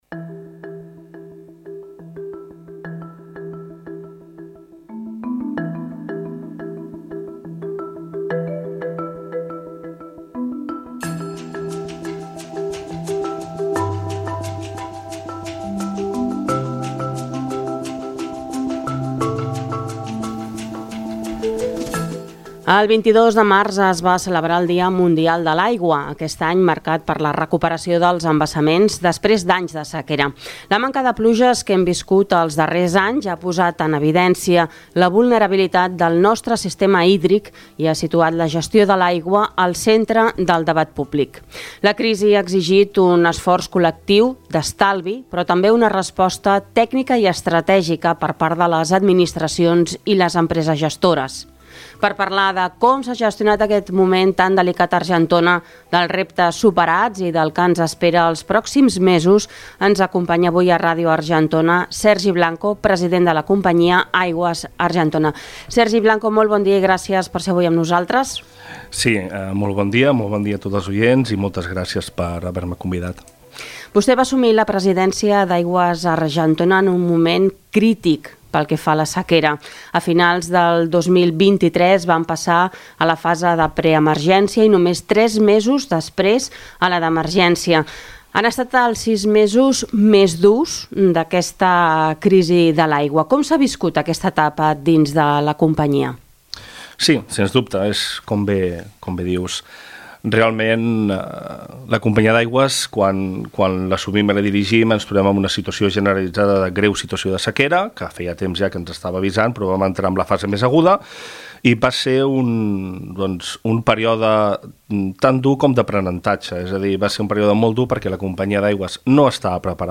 En una entrevista a Ràdio Argentona